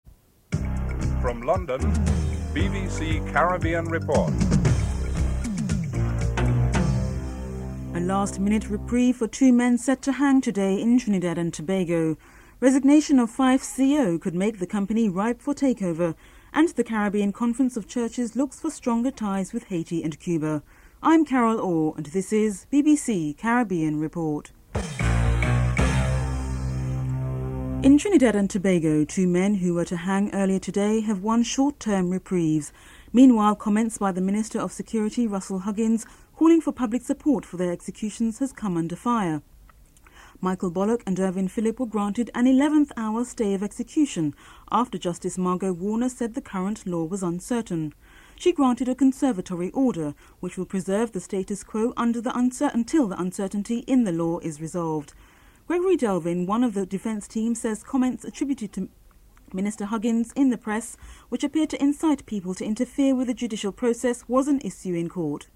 1. Headlines (00:00-00:34)